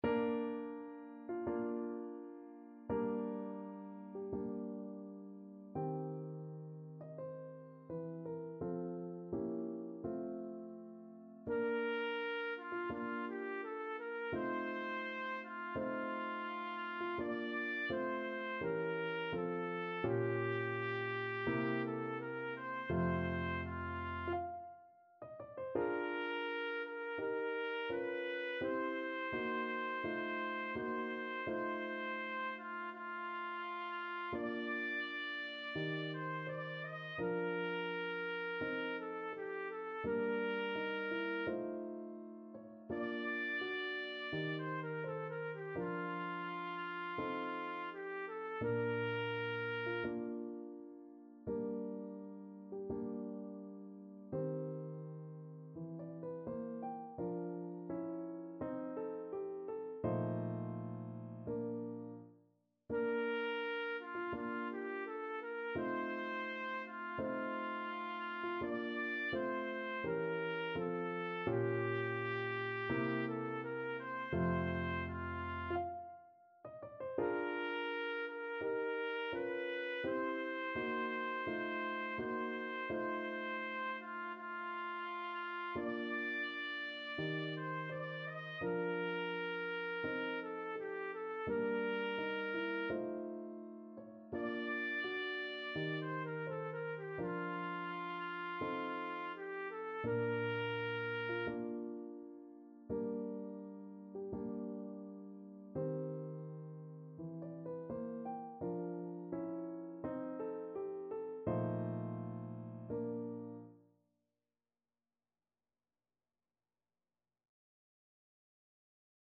Trumpet
2/4 (View more 2/4 Music)
~ = 42 Sehr langsam
Bb major (Sounding Pitch) C major (Trumpet in Bb) (View more Bb major Music for Trumpet )
Classical (View more Classical Trumpet Music)